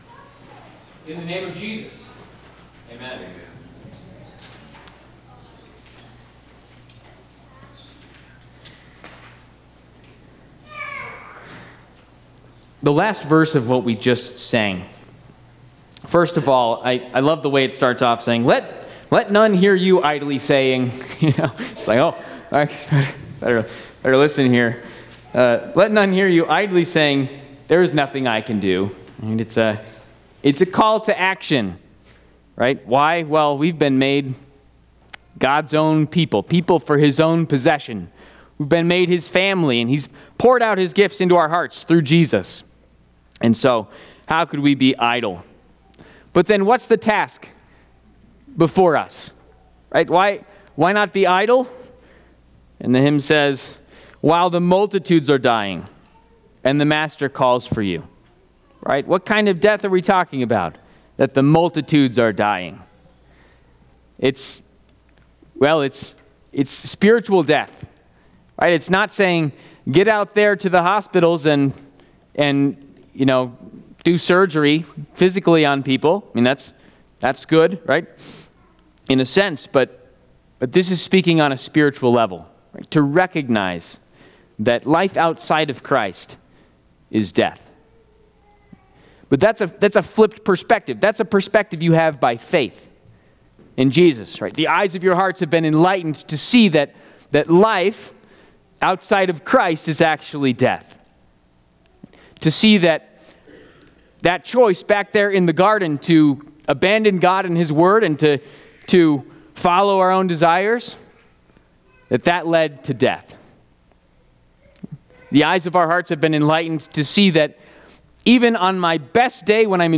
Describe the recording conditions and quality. Fourth Sunday after Pentecost&nbsp